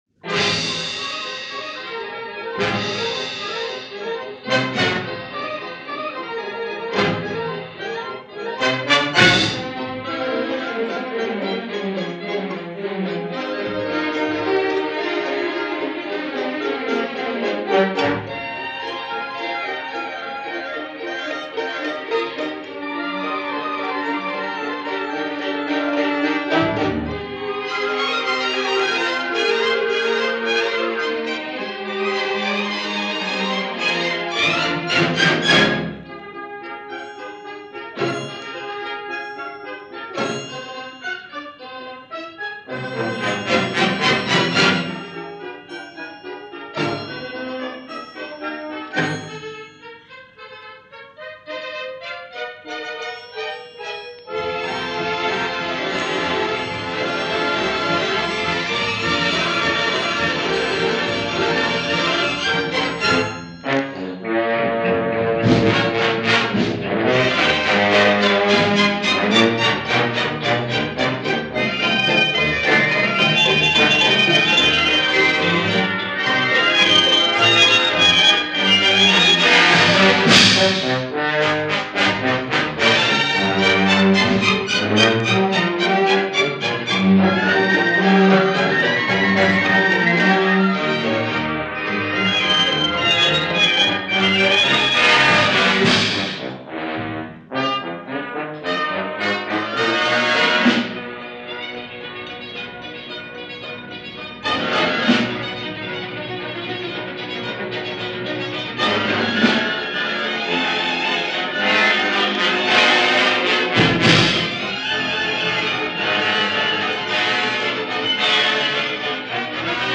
Eugène Bigot - Conductor of The FNO
Maurice Emmanuel – Overture pour une Conte Gai – French National Orchestra – Eugène Bigot, Cond. – Radio France broadcast, circa 1956.
This performance is features the legendary French Conductor/Composer/professor Eugène Bigot, leading the French National orchestra in a radio performance from circa 1955.